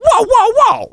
crow_get_hit_02.wav